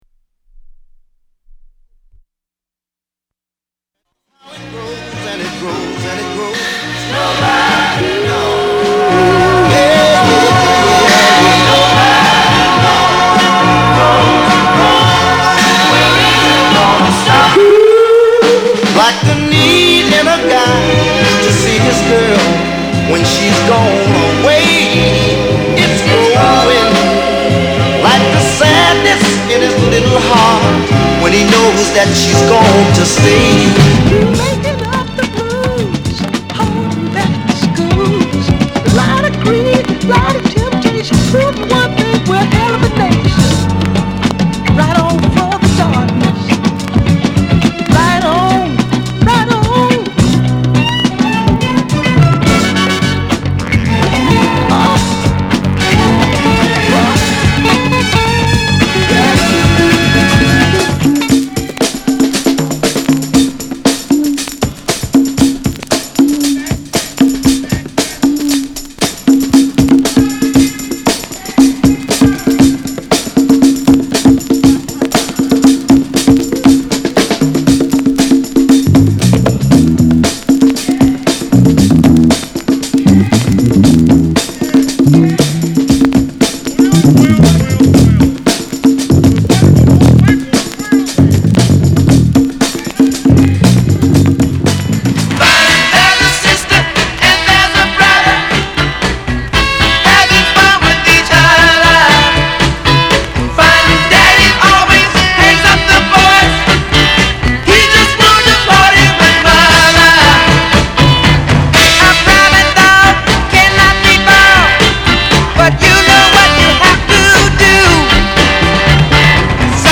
類別 R&B、靈魂樂